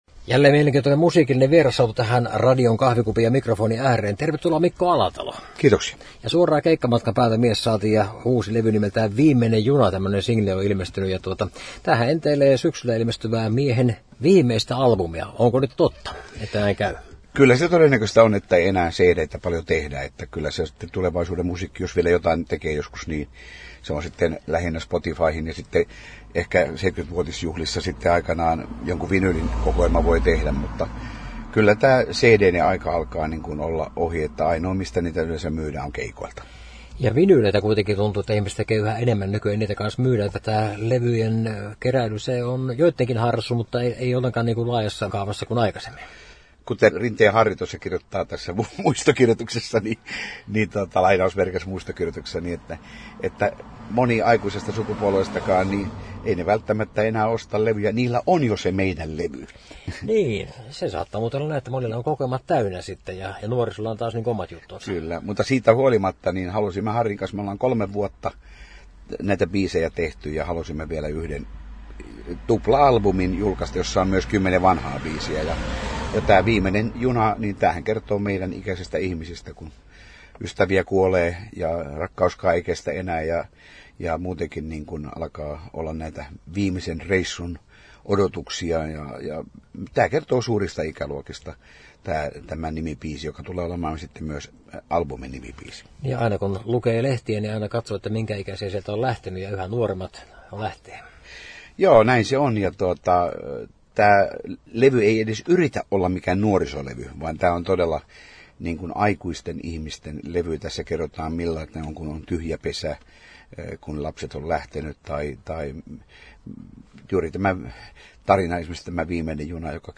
haastattelu